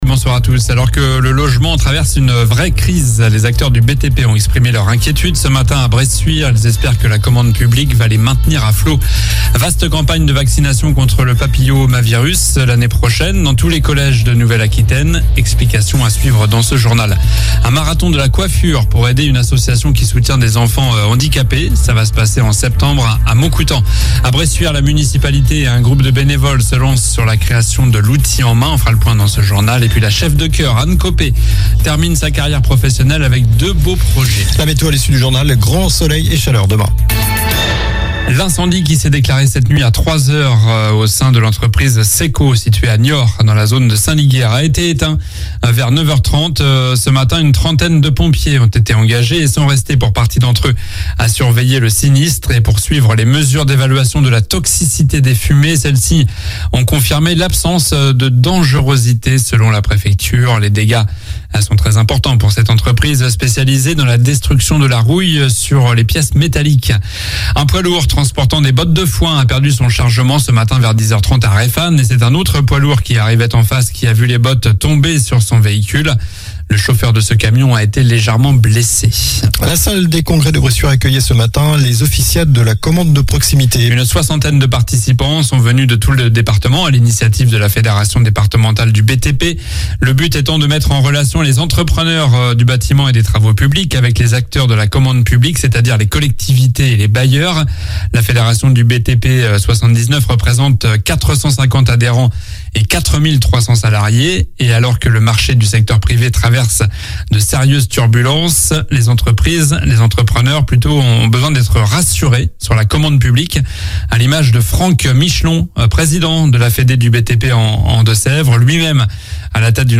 Journal du mardi 13 juin (soir)